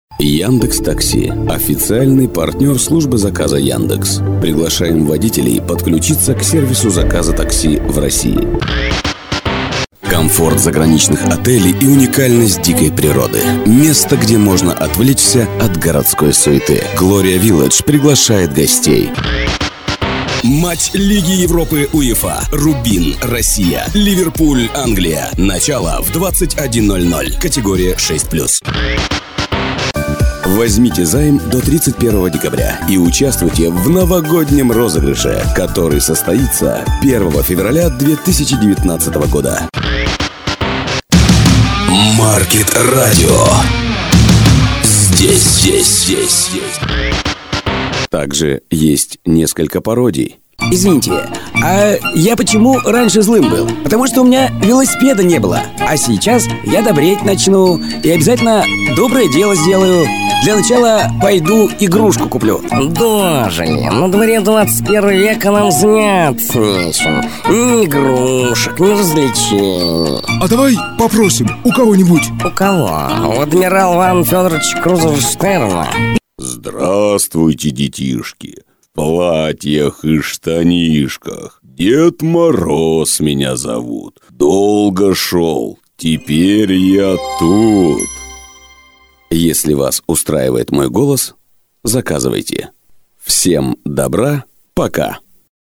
Пример звучания голоса
Муж, Другая
голос низкий, средний, информационный, анонсы, рекламные ролики, джинглы, аудиокниги, новостные сюжеты, и.т.д. по пожеланию заказчика.
мик Shure SM7B - голос проц DRAWMER MX 60 - пульт EMX 5016 Yamaha - звуккарта M - Audio 192 AUDIOPHILE